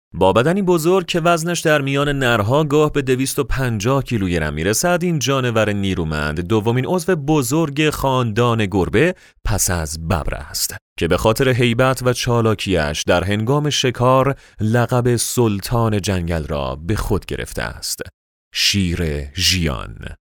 Male
Young
Adult
Now, he has his own home studio and can energetically read and record any type of script you can imagine.
Dacumentry